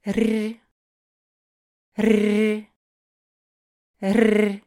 🔊 Hörprobe: Höre den Unterschied: So sanft klingt das weiche russische Р [rʲ].
r-russisch-weich.mp3